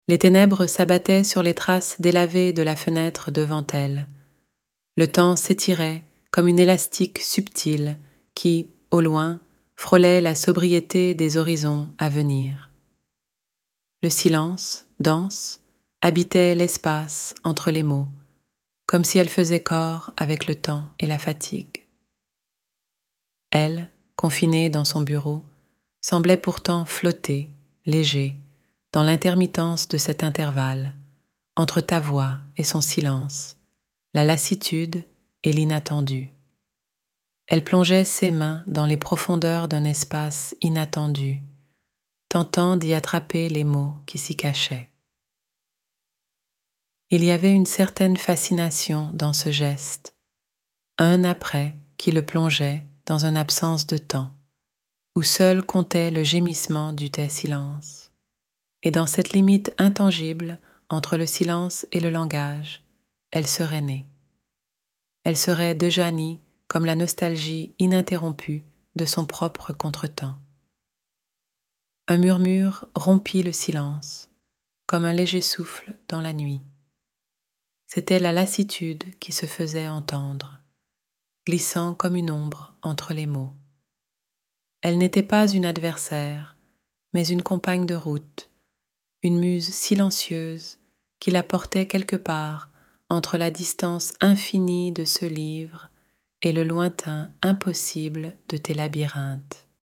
*Murmudõ0delå: Le murmure de l’oubli d’une forêt qui se tait en crescendo.